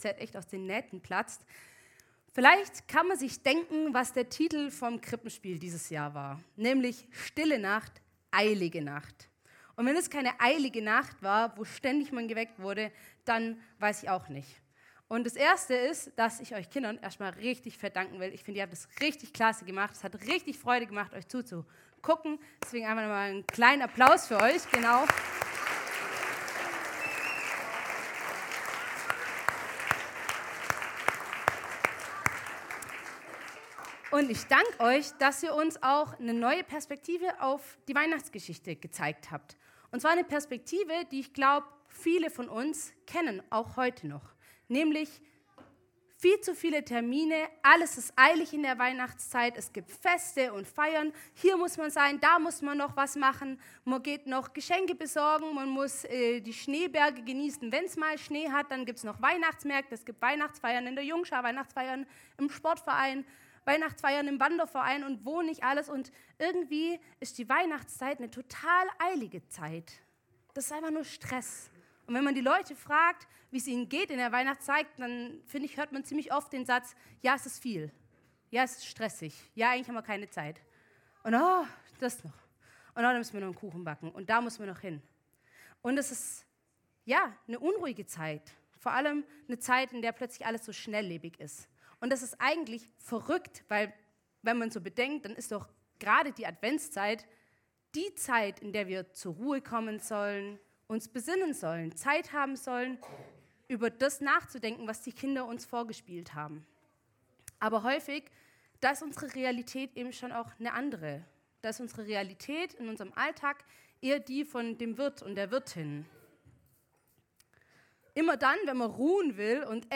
Gottesdienst 17.12.2023